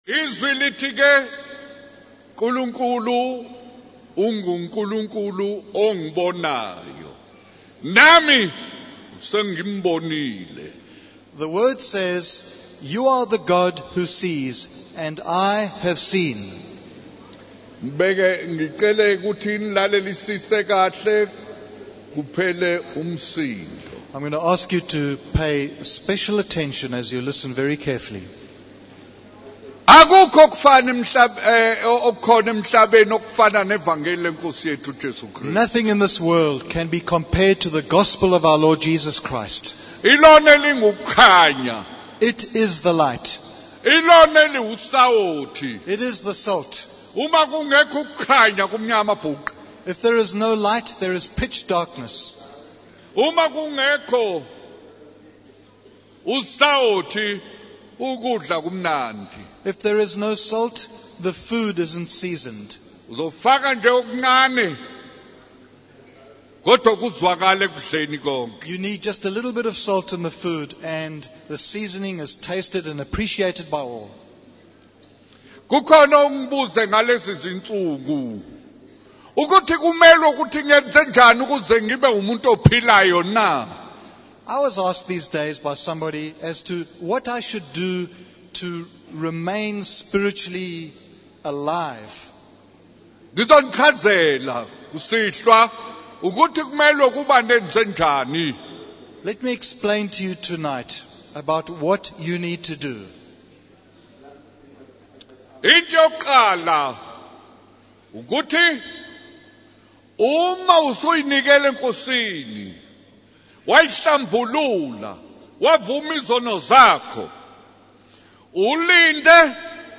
In this sermon, the preacher emphasizes the importance of the Gospel of Jesus Christ as the ultimate source of light and seasoning in our lives. He advises believers to remain spiritually alive by watching and praying, guarding against the influence of Satan. The preacher also addresses the issue of sexual immorality and the consequences it has on individuals and society. He urges young people to live according to the teachings of the Gospel and to be mindful of the prayers of their parents.